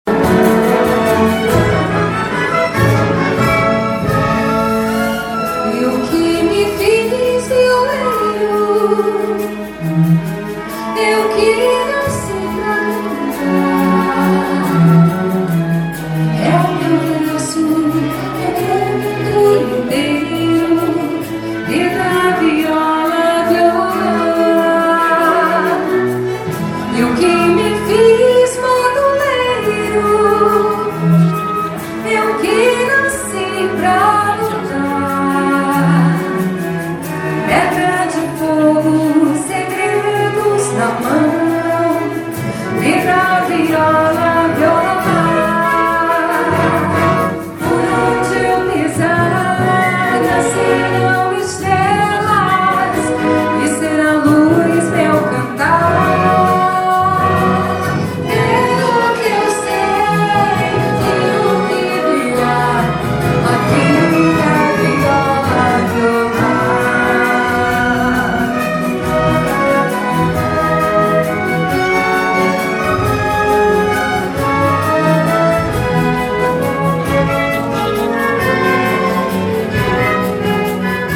04:25:00   Mpb